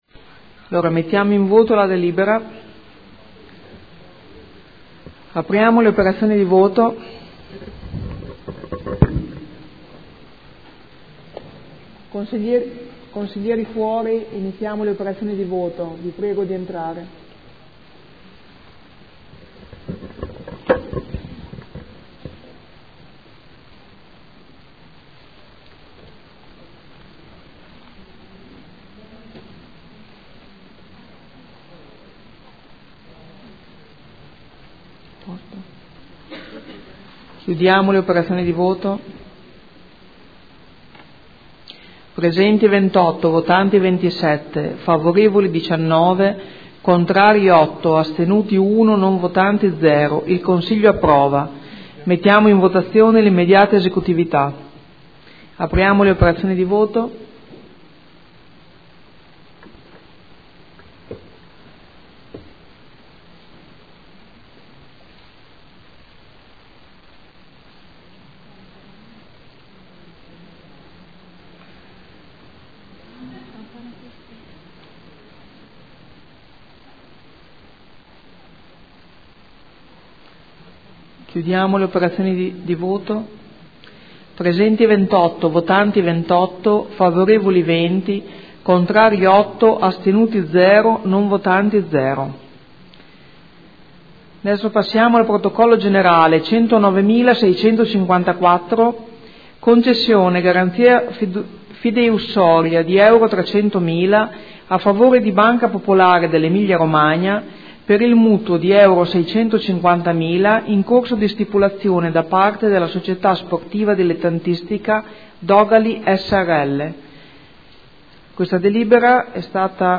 Seduta del 25/09/2014. Proposta di deliberazione: Bilancio di Previsione 2014-2016 – Programma triennale dei lavori pubblici 2014-2016 – Stato di attuazione dei programmi e verifica degli equilibri di bilancio – Variazione di bilancio n. 4 e ratifica della deliberazione della Giunta comunale n. 384 del 5.8.2014 – Variazione di bilancio n. 3.